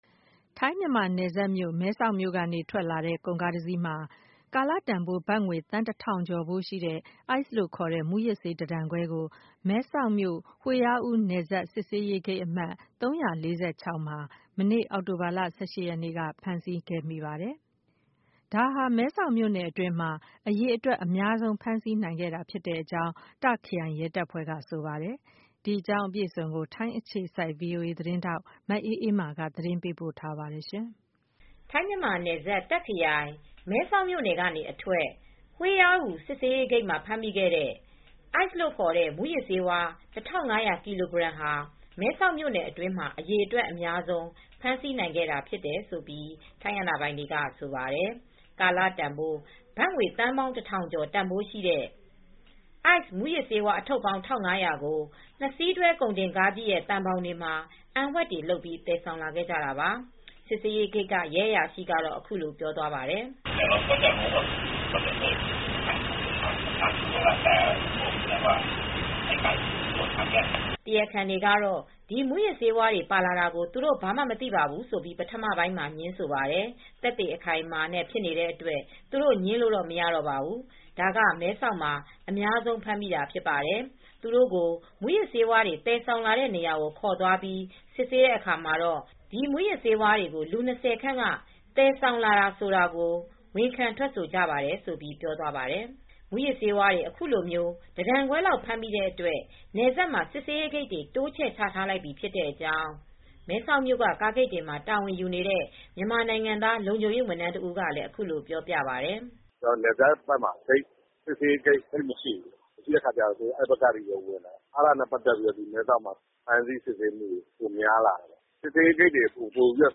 ကာလတန်ဖိုး ဘတ်သန်းပေါင်း ၁၀၀၀ ကျော်တန်ဖိုးရှိတဲ့ အိုက်စ်မူးယစ်ဆေး အထုပ်ပေါင်း ၁၅၀၀ ကို နှစ်စီးတွဲ ကုန်တင်ကားကြီးရဲ့ သံဘောင်တွေမှာ အံဝှက်တွေလုပ်ပြီး သယ်လာခဲ့ကြတာပါ။ စစ်ဆေးရေးဂိတ်က ရဲအရာရှိက အခုလိုပြောပါတယ်။